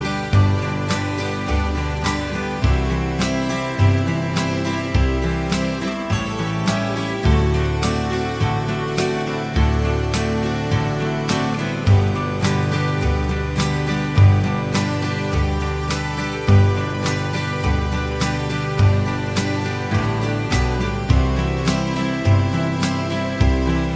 Irish